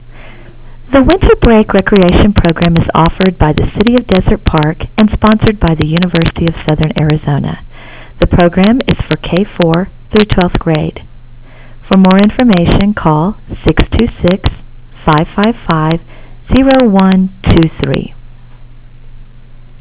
Find out what an attendee from last year's Wnter Break program has to say.